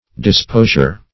Disposure \Dis*po"sure\, n. [From Dispose.]